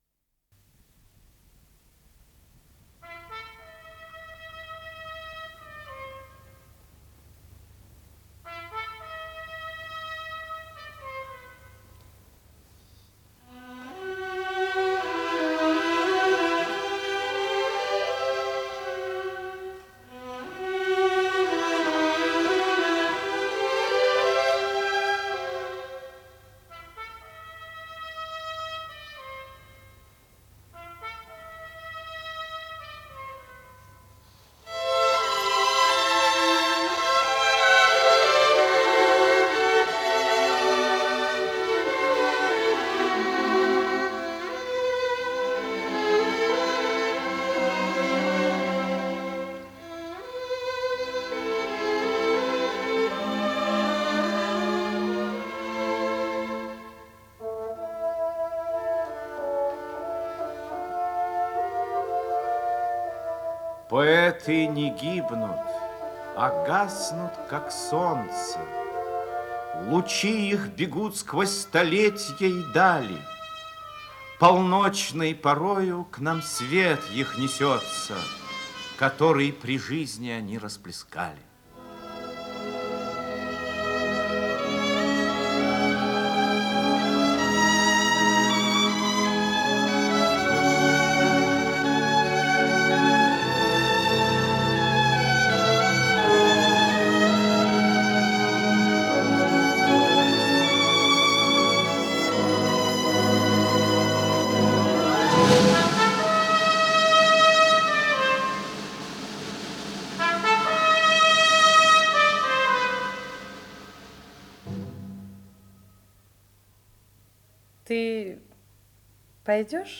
Исполнитель: Артисты московских театров
Радиопостановка